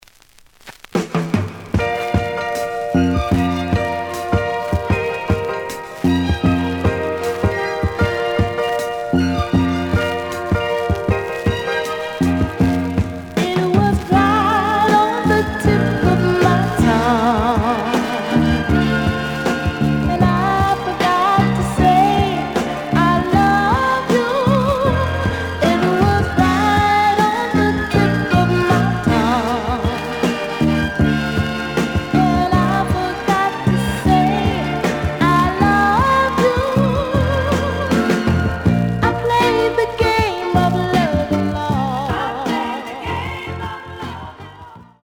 試聴は実際のレコードから録音しています。
The audio sample is recorded from the actual item.
●Format: 7 inch
●Genre: Soul, 70's Soul